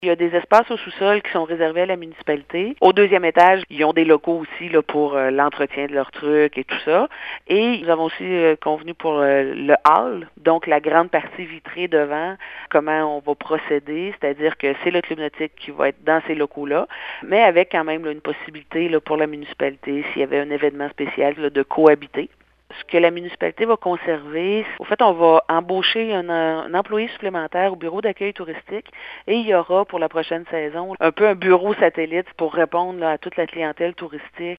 La mairesse de Percé, Cathy Poirier :